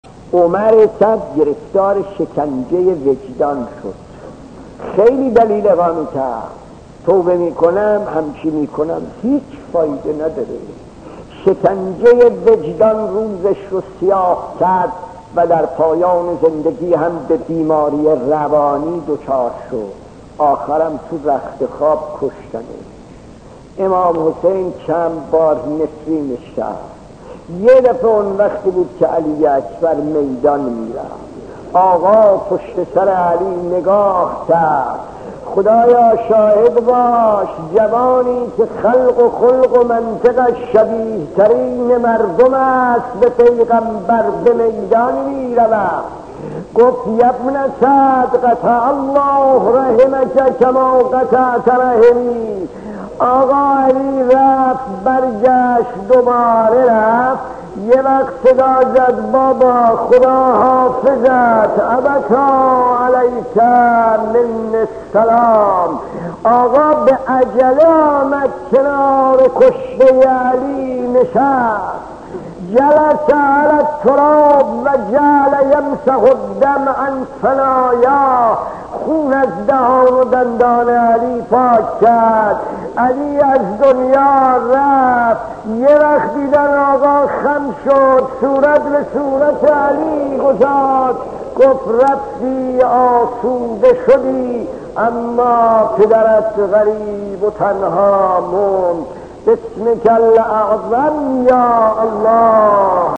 داستان 19 : عمر سعد و شکنجه وجدان خطیب: استاد فلسفی مدت زمان: 00:01:42